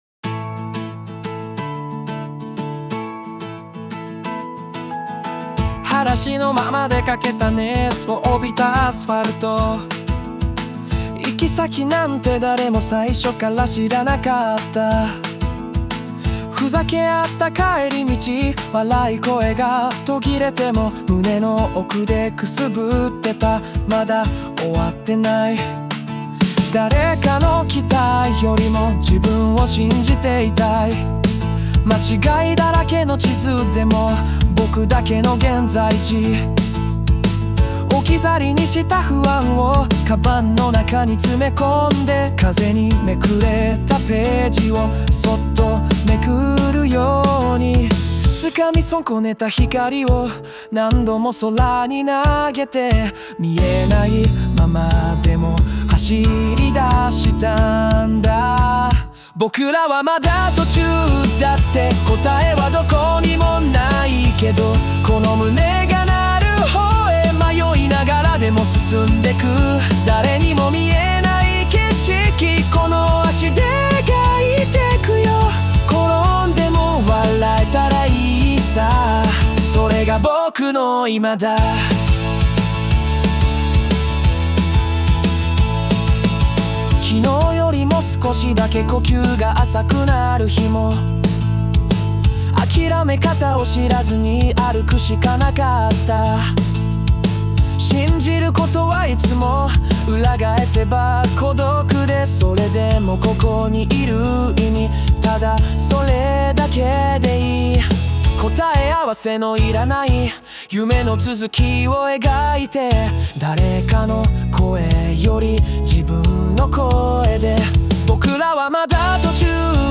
邦楽男性ボーカル著作権フリーBGM ボーカル
著作権フリーオリジナルBGMです。
男性ボーカル（邦楽・日本語）曲です。
青春ソングとして結婚式のプロフィールムービーの新郎パートにもオススメです✨